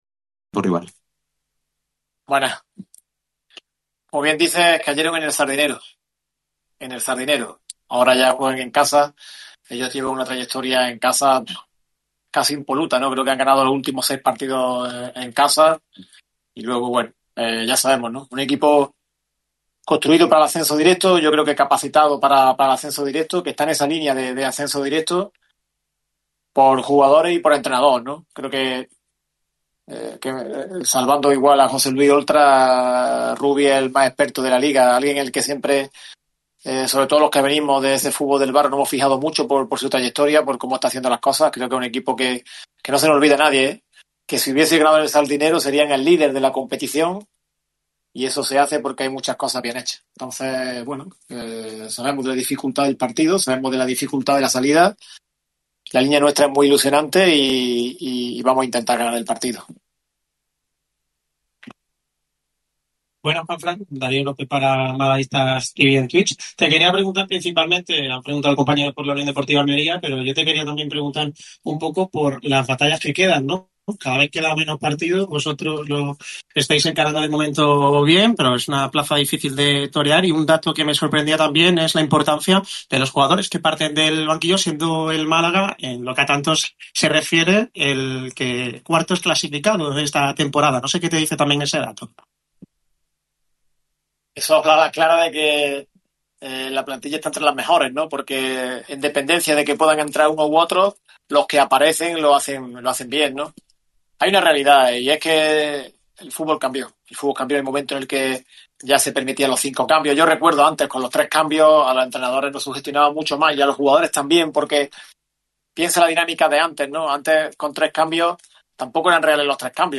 Declaraciones íntegras
ante los medios en sala de prensa. El club malaguista afronta un partido muy importante ante los indálicos con la posibilidad de pisar los puestos de ascenso directo.